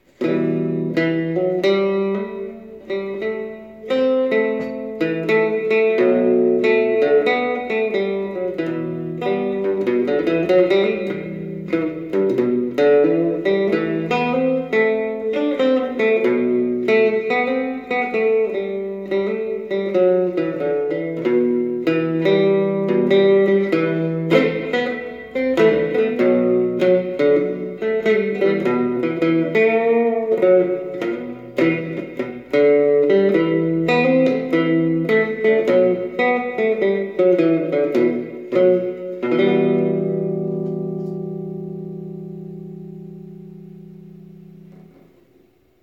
cookie banjo
Pure DIY: Save a tin box, attach a neck to it, put on 4 strings in open G (GDGB) and just play and enjoy the sweet metallic sound. Ideal for practising at night because of their low volume, but powerful for jamming when plugged in!
unplugged
amplified
The piezo pickup is located on the neck extension rod.